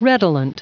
Prononciation du mot redolent en anglais (fichier audio)
Prononciation du mot : redolent